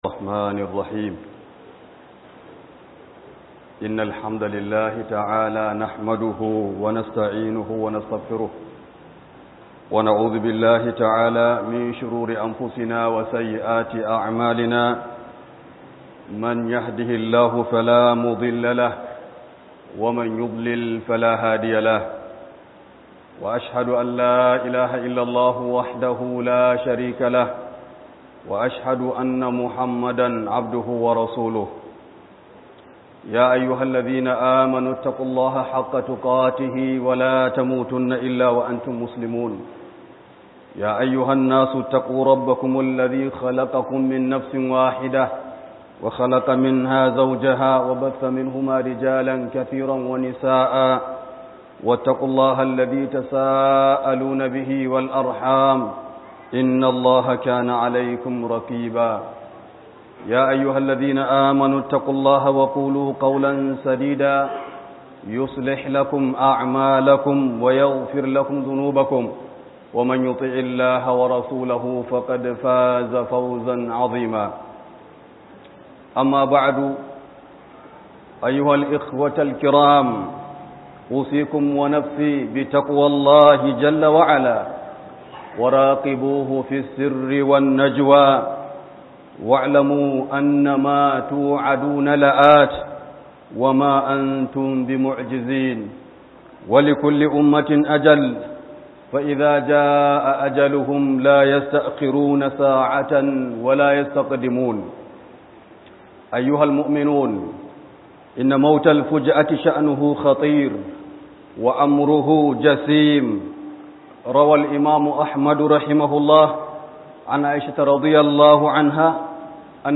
Play Radio
Book HUDUBA